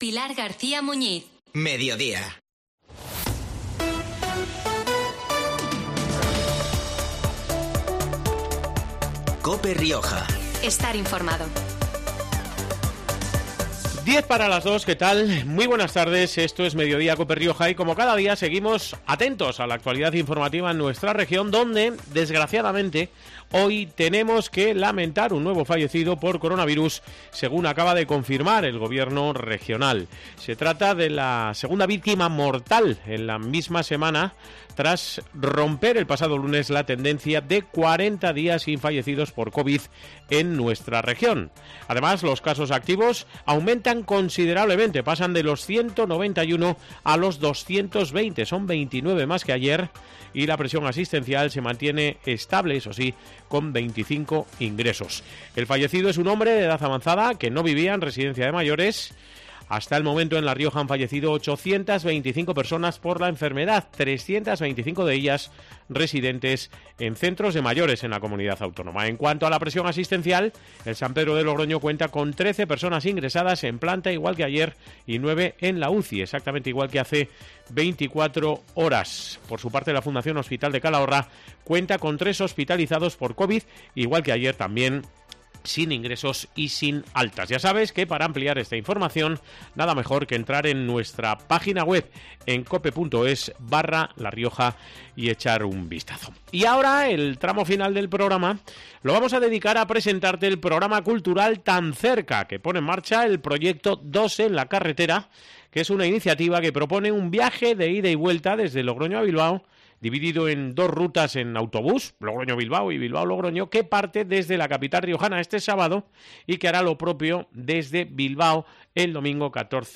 El tramo final del programa de este jueves lo hemos dedicado a presentar el programa cultural 'Tan cerca' , que pone en marcha el proyecto ' Dos en la carretera ', un iniciativa que propone un viaje de ida y vuelta desde Logroño a Bilbao , dividido en dos rutas en bus (Logroño-Bilbao y Bilbao-Logroño) que parte desde la capital riojana este sábado y desde Bilbao el domingo, 14 de noviembre .